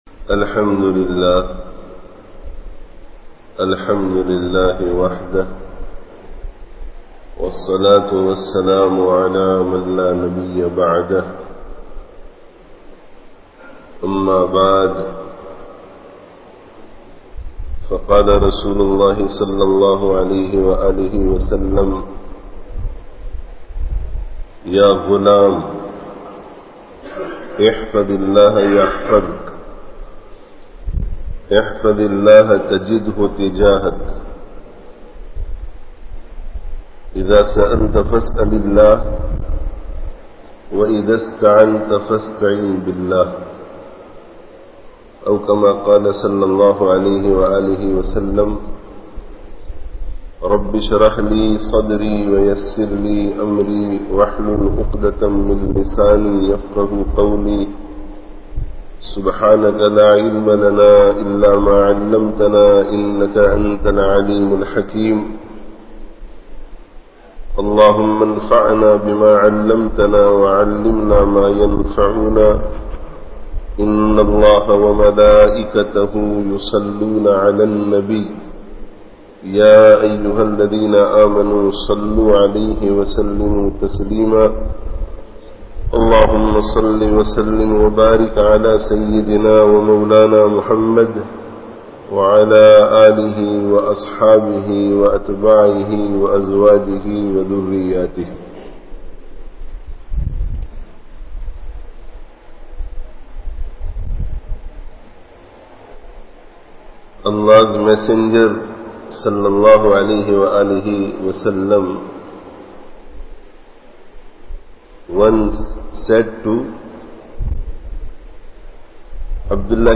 How to Acquire Allāh’s Protection (Jumu'ah Bayan, Jamia Masjid, Barbados 28/04/17)